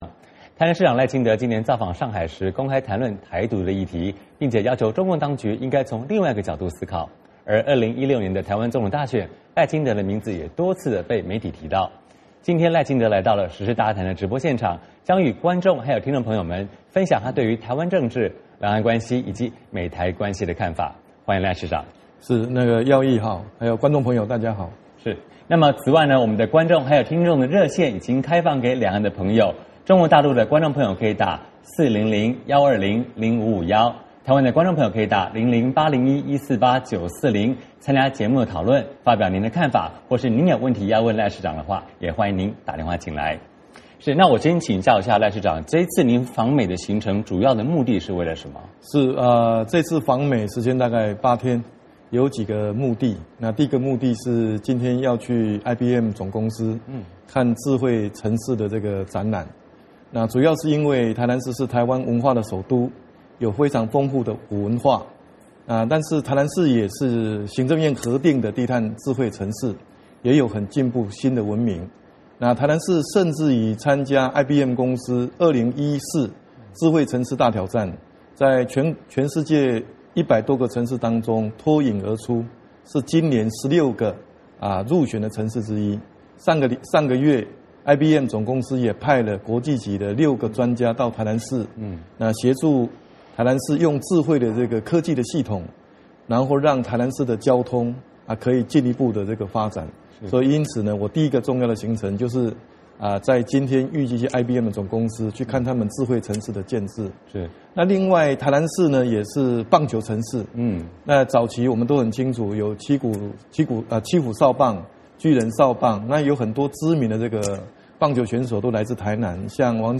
时事大家谈：专访台南市市长赖清德
今天赖清德来到《时事大家谈》的直播现场，将和观众听众朋友们分享他对台湾政治、两岸关系，以及美台关系的看法。